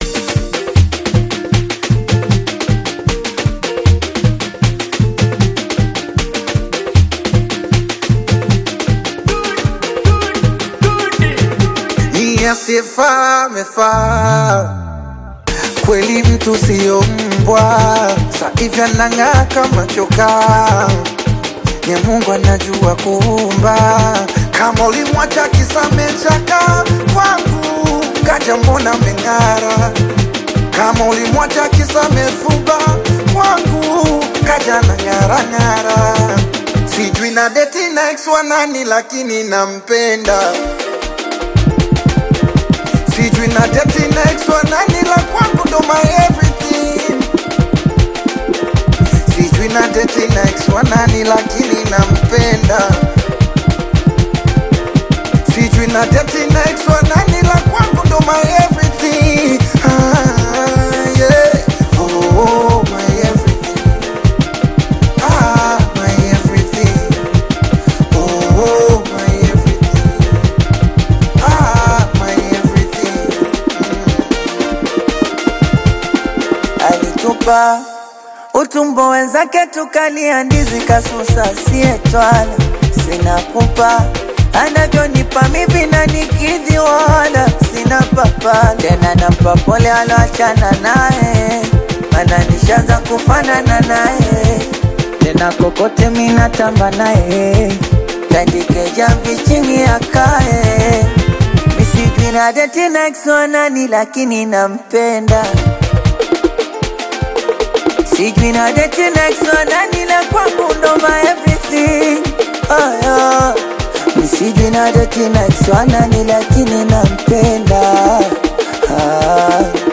vibrant Singeli/Bongo Flava collaboration
high-energy rhythms
catchy melodies
Genre: Singeli